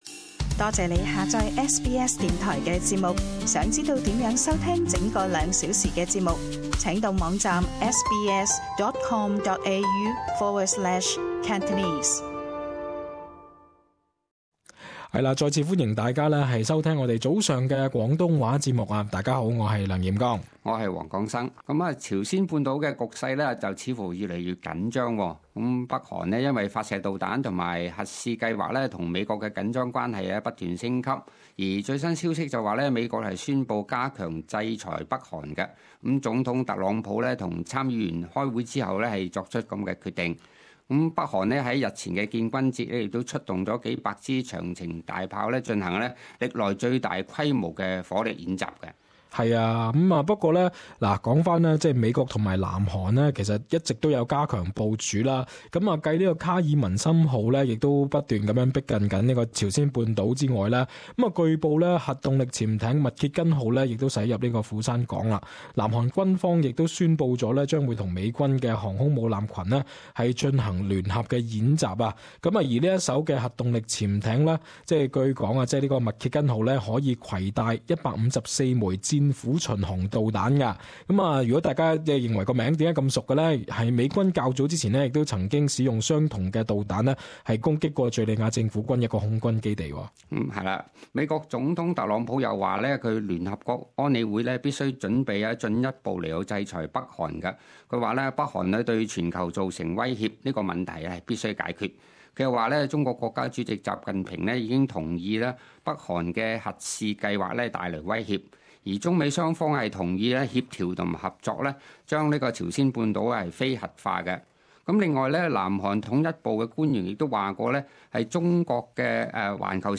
与听众朋友一同讨论大家是否担心朝鲜半岛开战，与澳洲是否会直接受威胁等话题。